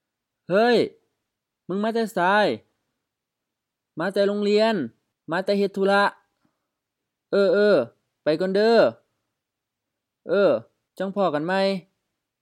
BCF01 Greetings and leave takings (informal) — Dialogue C